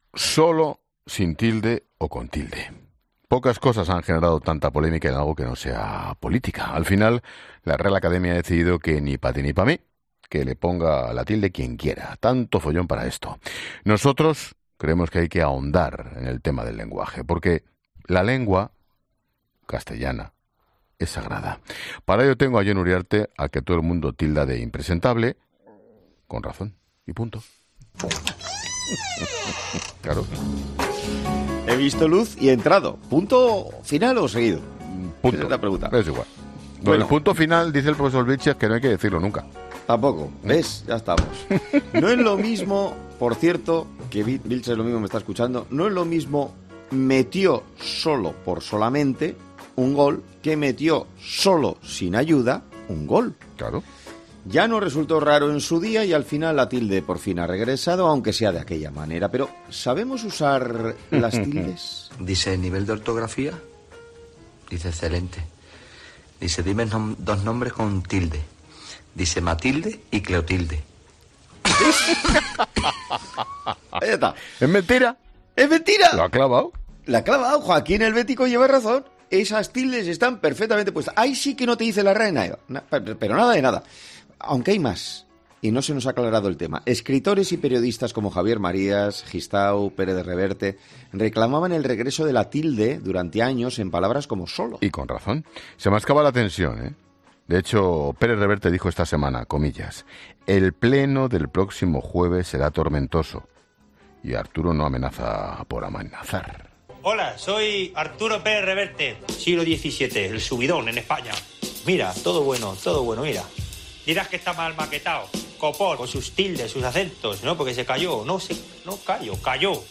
El chiste de Joaquín Sánchez sobre ortografía con el que Expósito no puede parar de reír: "¡Lo ha clavado!"
Expósito no podía contener las risas: “Buensímo Arturo Valls”.